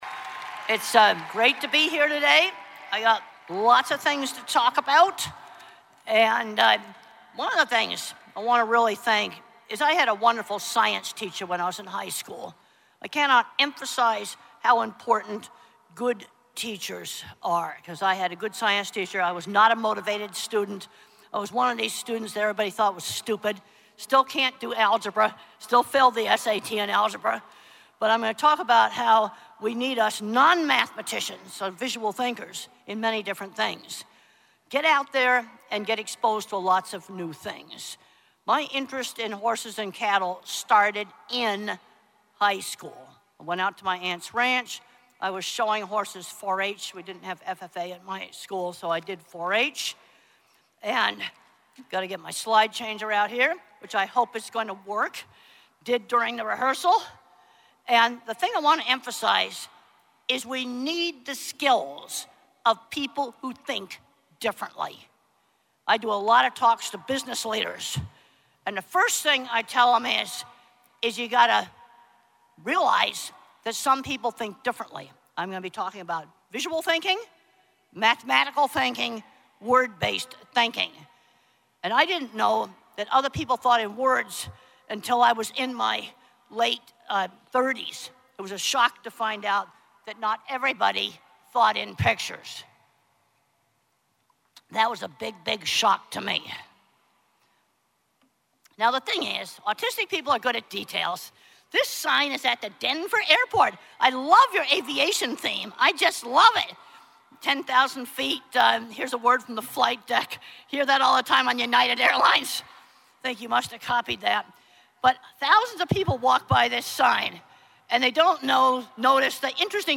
Keynote speaker Temple Grandin helped kick off the fourth general session of the 97th National FFA Convention and Expo, sharing her perspective on visual thinking